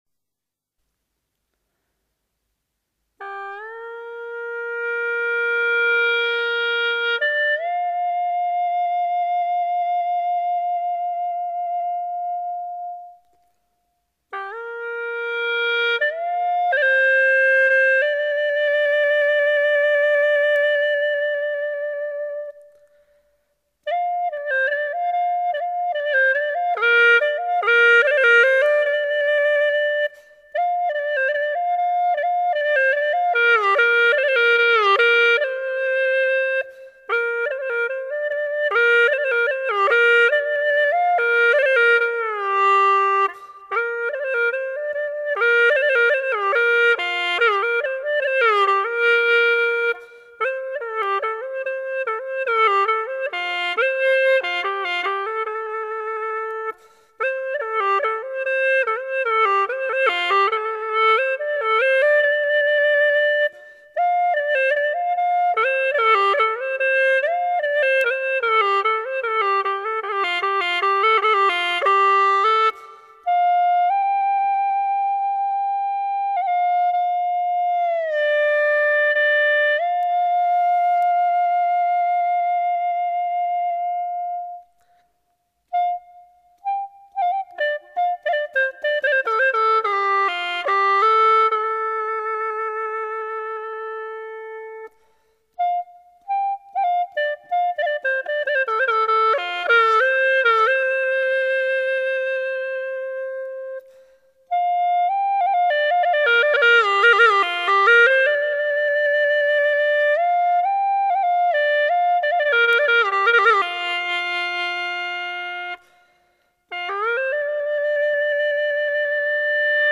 调式 : 降B